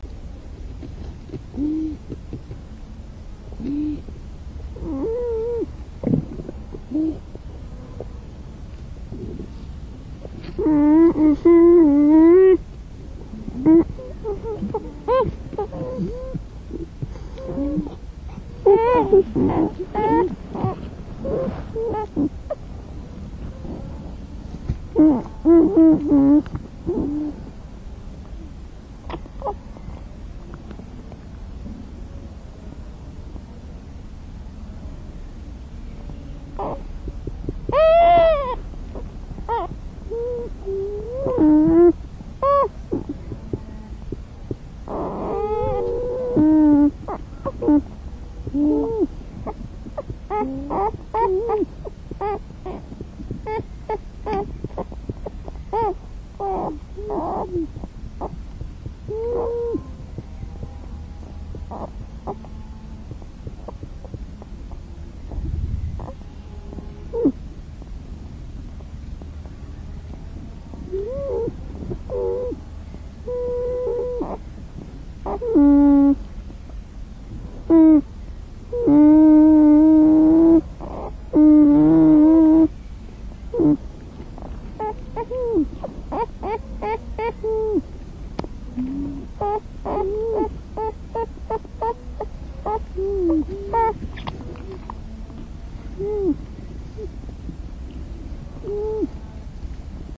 the last years – mainly inside of the beaver lodges!
Eurasian Beaver Castor fiber, adult and juvenile, calls, near Leipzig, Germany
WS_beaver_adult_juvenile_call.mp3